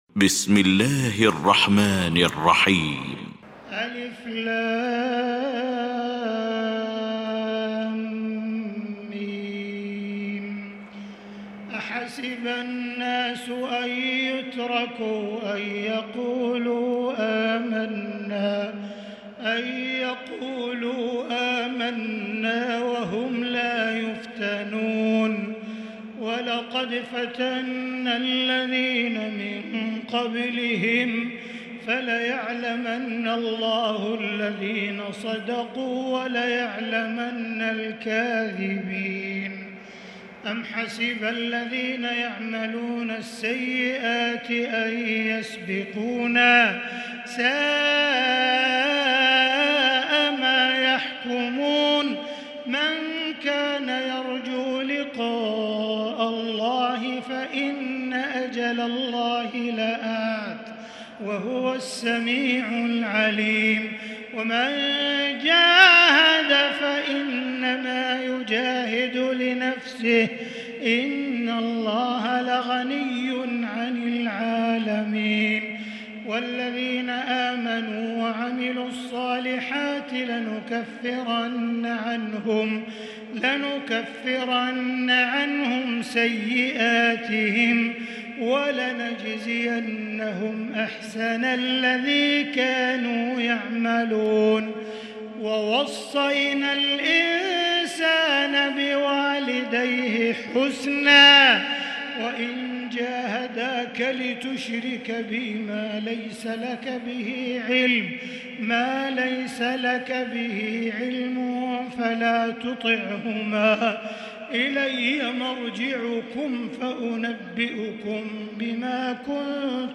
المكان: المسجد الحرام الشيخ: معالي الشيخ أ.د. عبدالرحمن بن عبدالعزيز السديس معالي الشيخ أ.د. عبدالرحمن بن عبدالعزيز السديس فضيلة الشيخ عبدالله الجهني العنكبوت